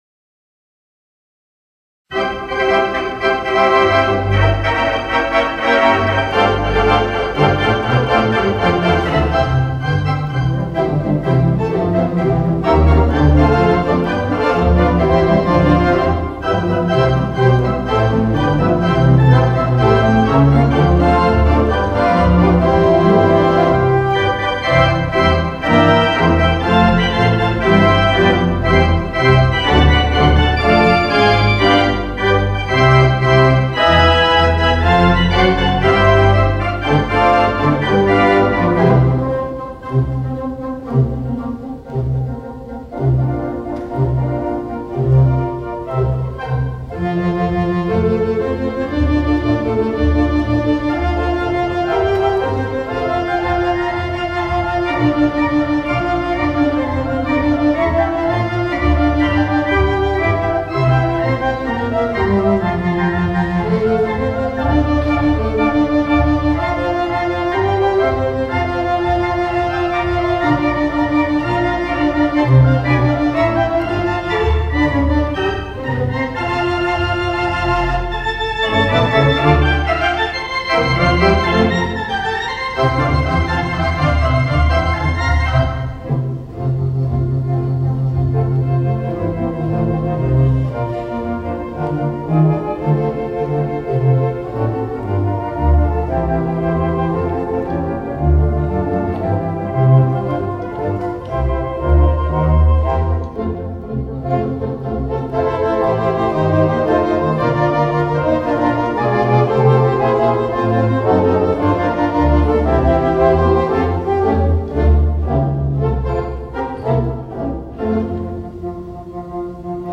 Mighty 2/5 Wicks Theatre Pipe Organ
and in front of an audience of over 200 attendees